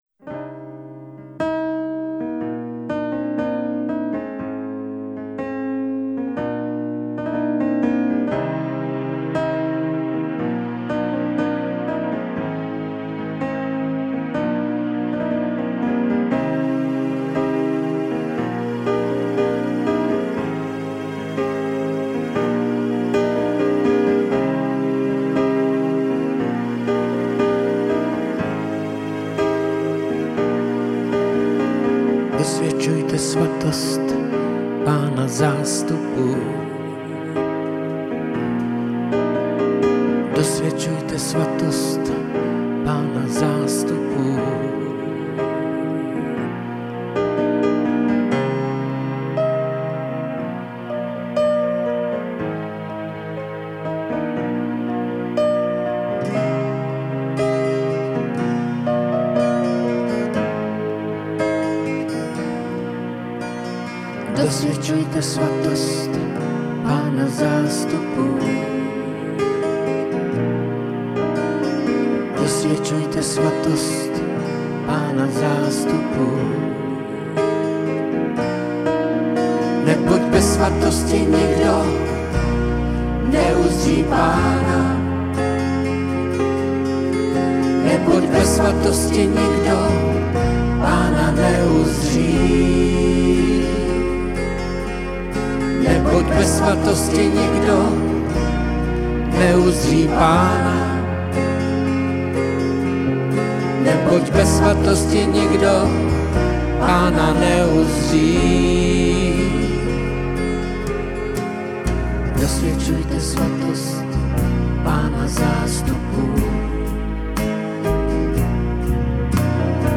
Písně ke chvále a uctívání